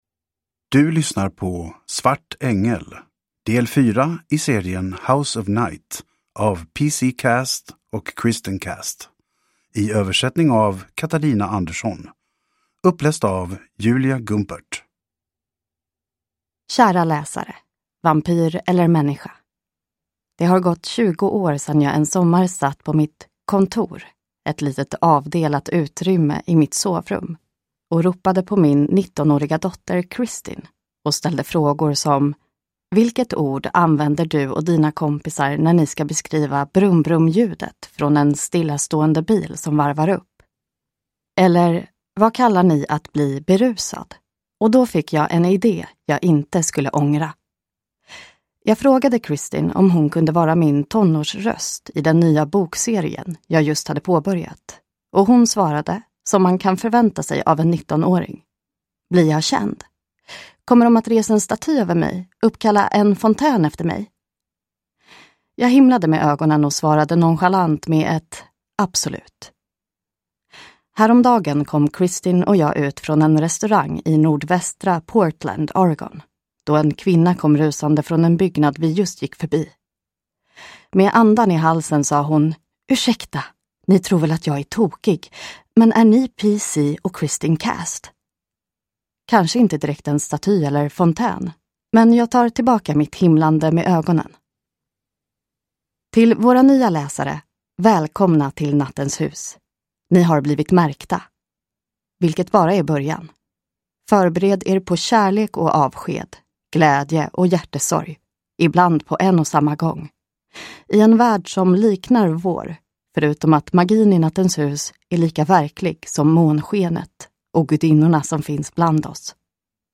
Svart ängel – Ljudbok